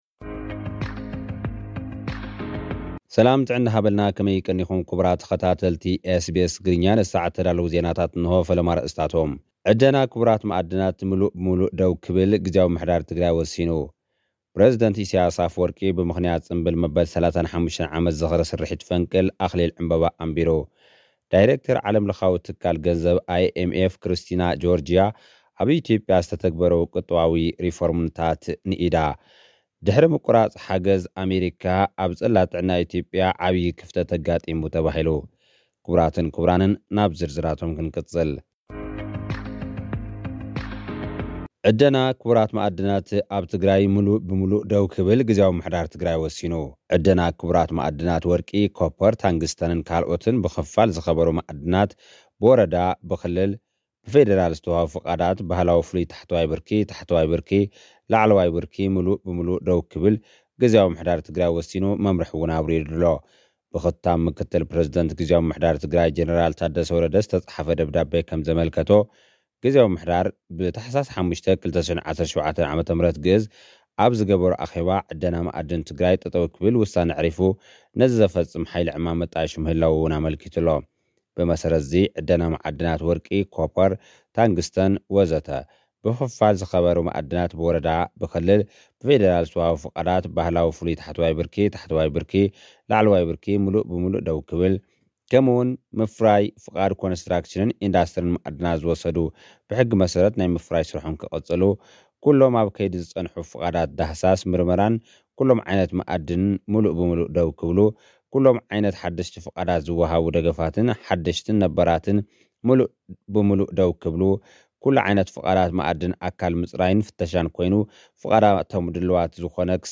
ድሕሪ ምቍራጽ ሓገዝ ኣመሪካ፡ ጽላት ጥዕና ኢትዮጵያ ዓቢ ክፍተት ኣጋጢምዎ። (ጸብጻብ)